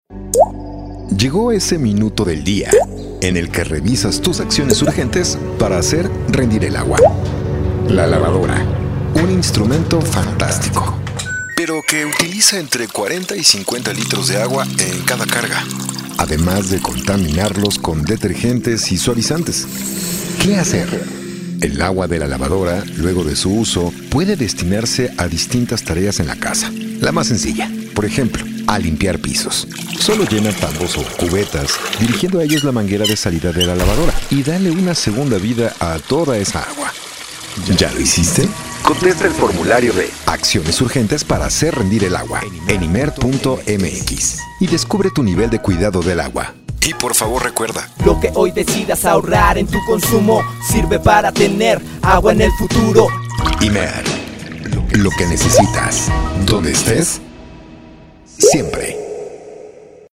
ACTUACIÓN DRAMÁTICA